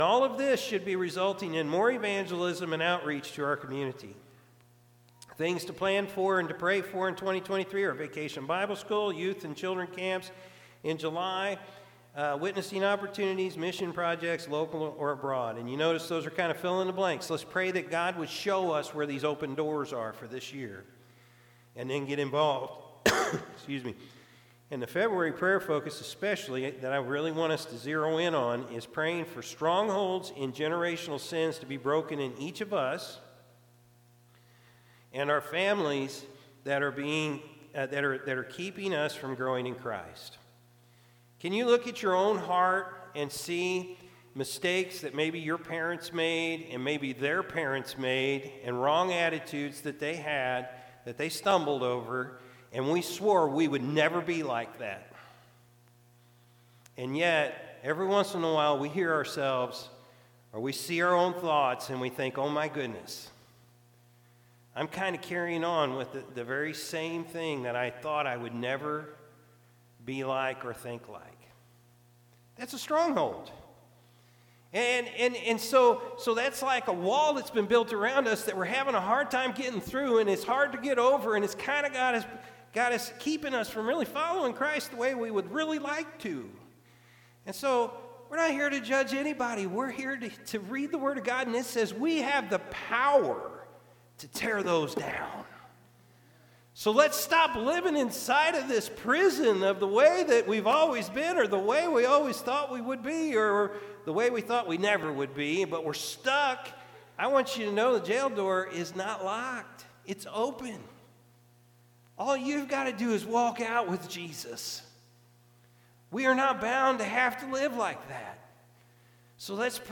February-5-2023-Morning-Service.mp3